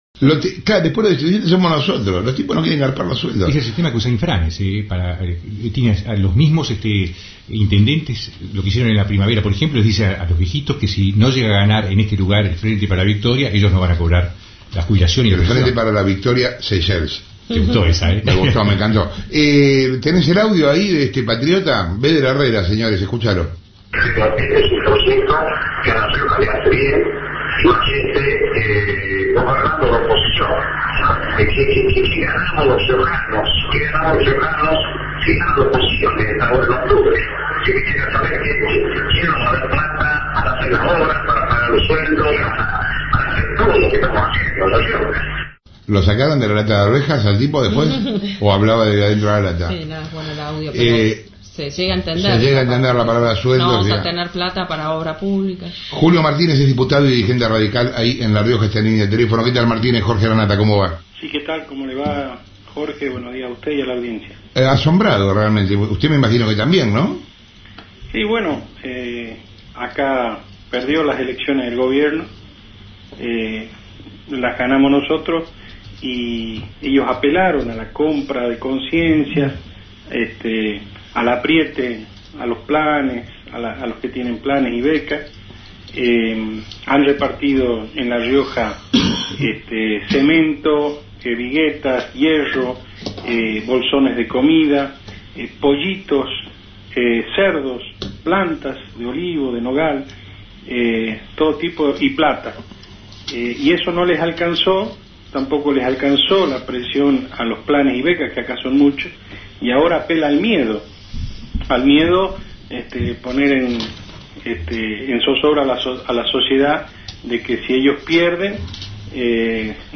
Lanata dedicó dos capítulos de su programa de Radio Mitre sobre las declaraciones del mandatario riojano, en uno de los días con mayor audiencia de la emisora porteña.
Luego, el periodista consideró una «brutalidad» las afirmaciones de Beder Herrera y más tarde, realizó una entrevista al diputado radical Julio Martínez, quien afirmó que el gobierno de La Rioja «es el más corrupto» de toda la historia.